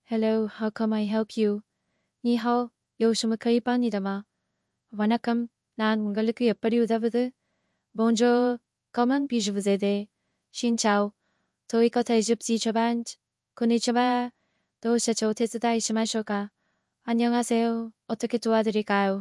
Qwen3-1.7B-Multilingual-TTS